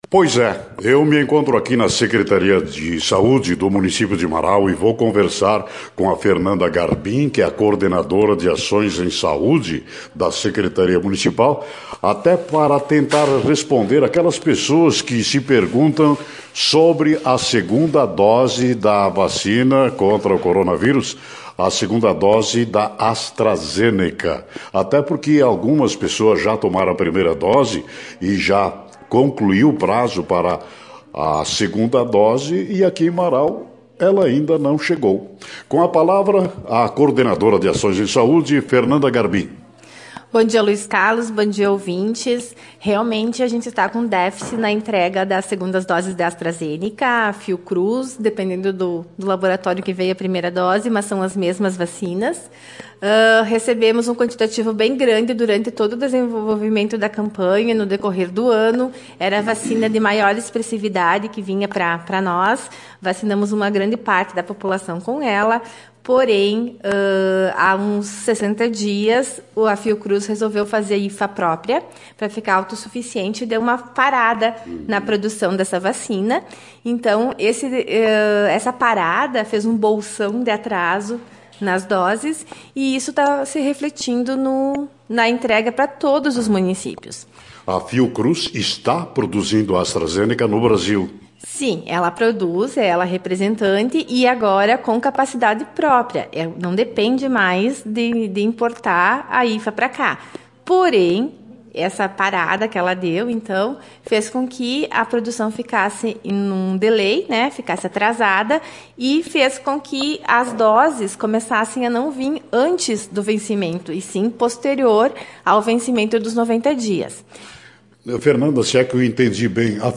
A entrevista completa